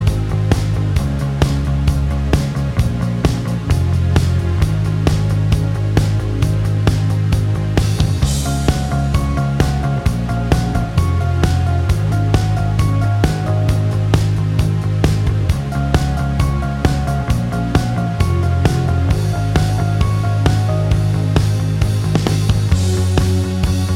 No Guitars Pop (2010s) 4:03 Buy £1.50